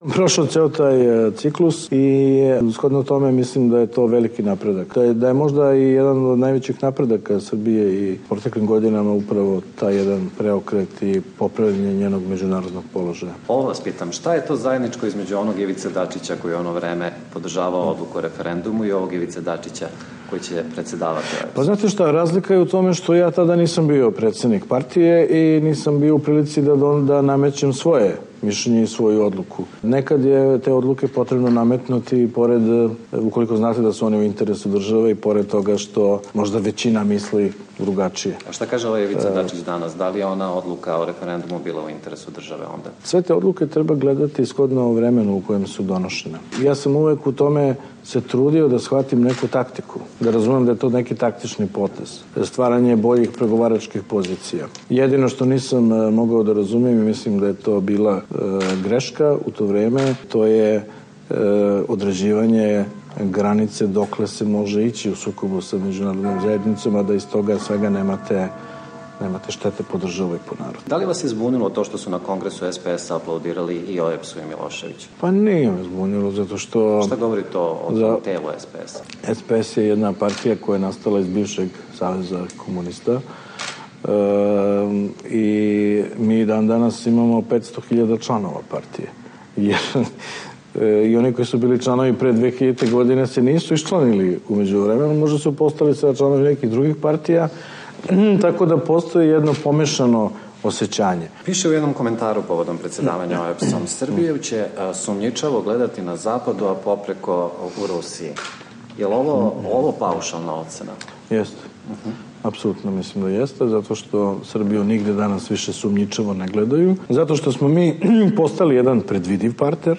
Intervju
Intervju nedelje - Ivica Dačić